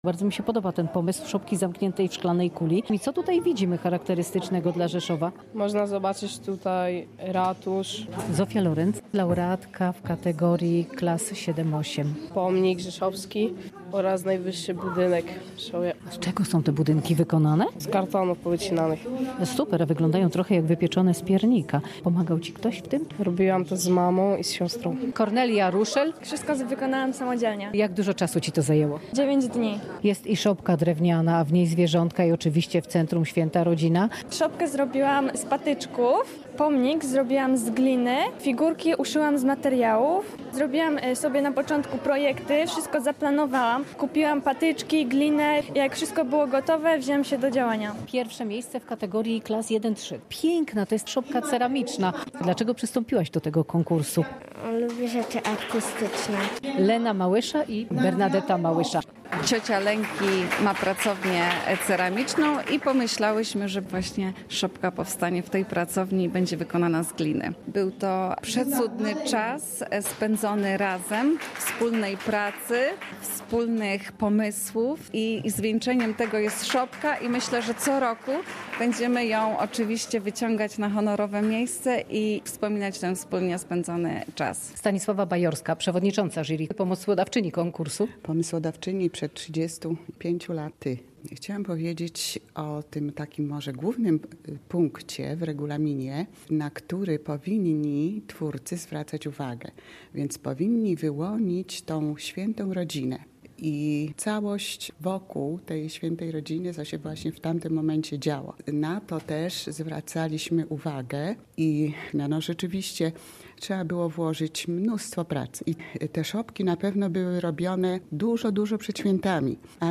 Uroczyste wręczenie nagród
Dziś w Rzeszowskich Piwnicach odbyło się uroczyste ogłoszenie wyników i wręczenie nagród.